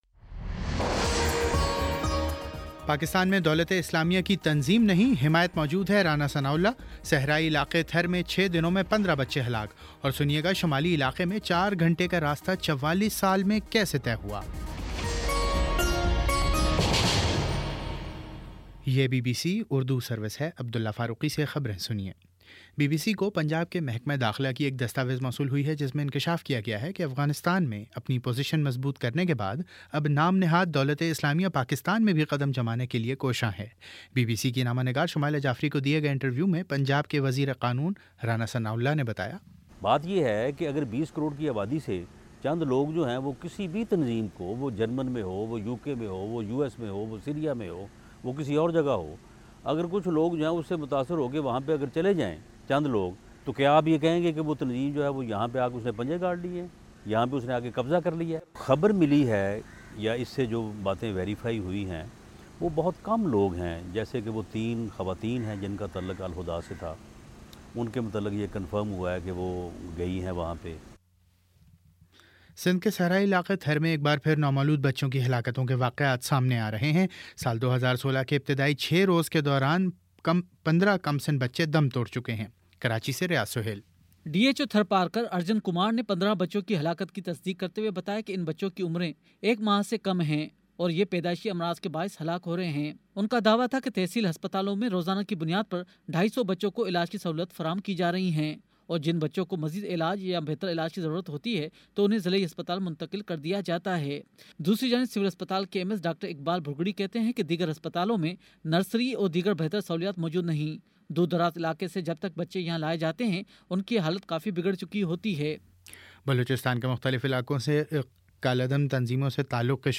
جنوری 06: شام سات بجے کا نیوز بُلیٹن